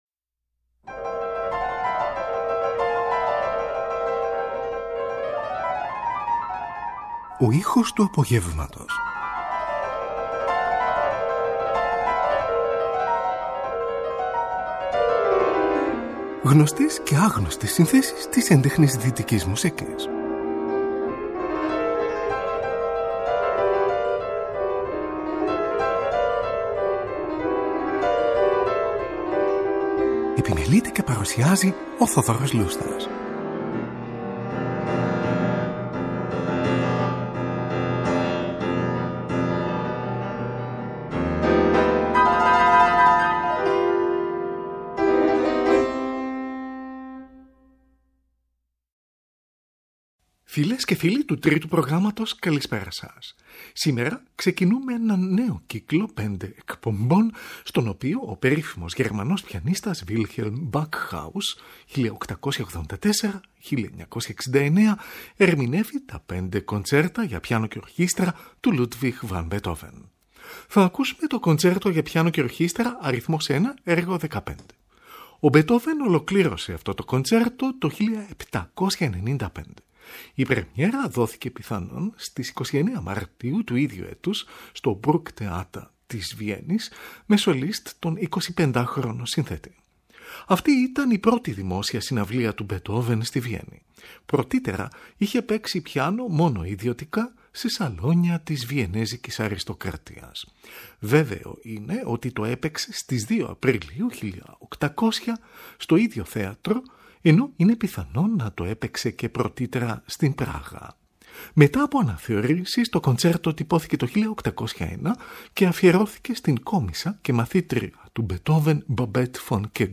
από ζωντανή ηχογράφηση.